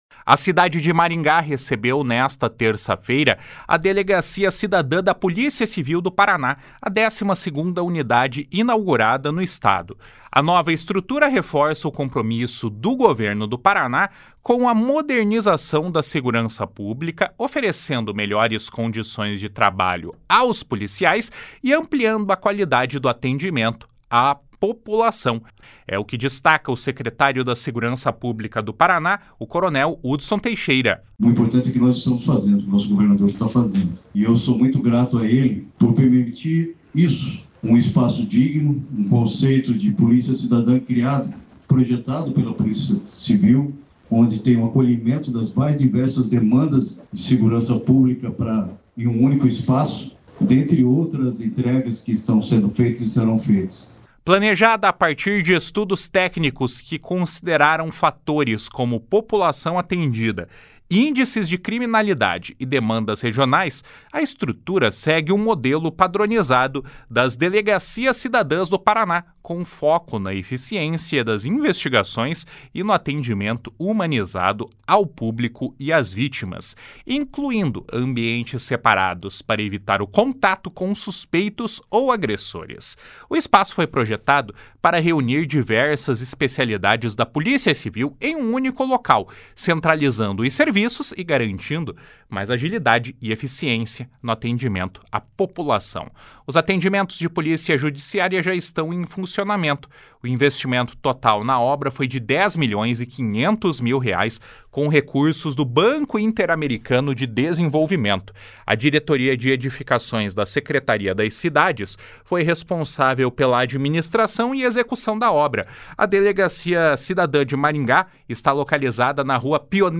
// SONORA HUDSON LEÔNCIO TEIXEIRA //